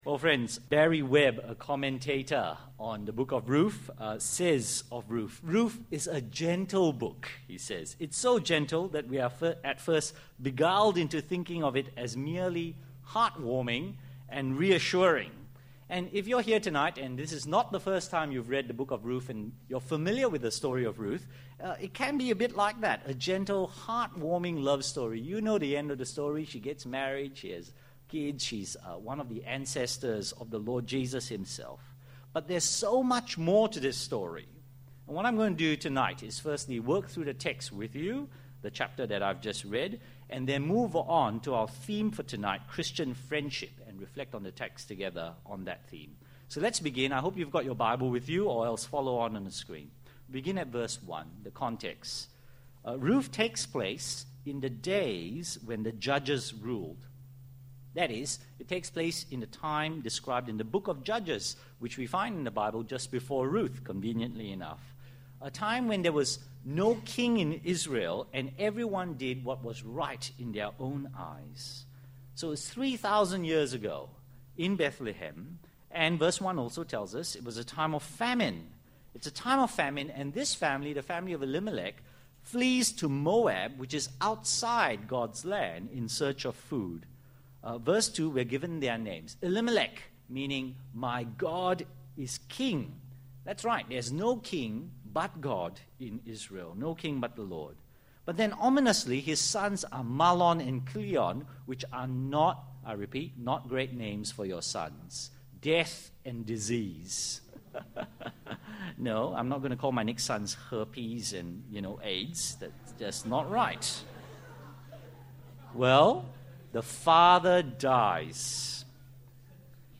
Redeeming Relationships. A sermon series on the book of Ruth.